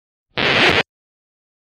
Здесь вы найдете характерные потрескивания, мягкие шипения и другие аутентичные звуки аналоговой эпохи.
Звук иголки соскальзывающей с виниловой пластинки